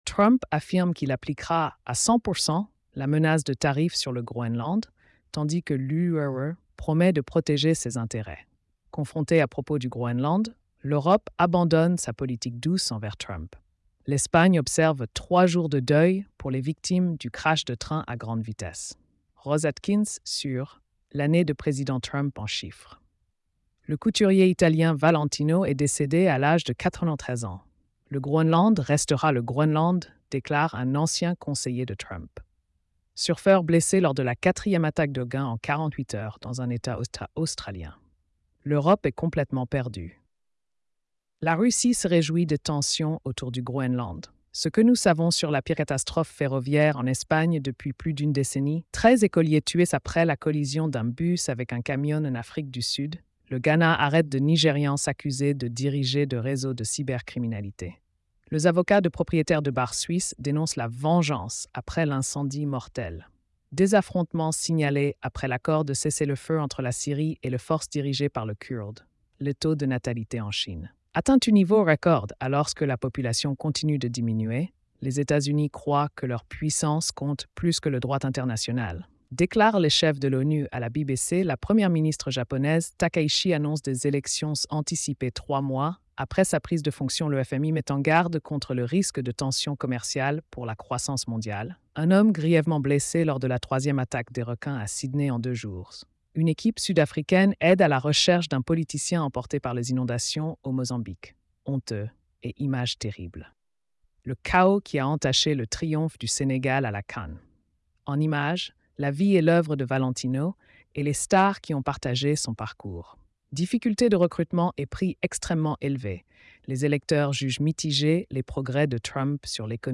🎧 Résumé des nouvelles quotidiennes. |